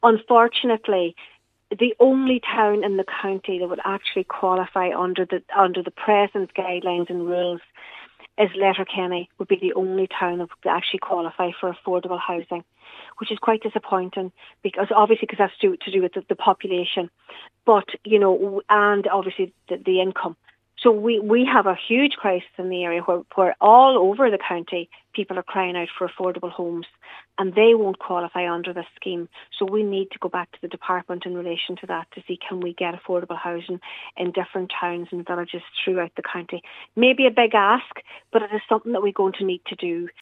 Cathaoirleach Cllr Niamh Kennedy says that must change……………..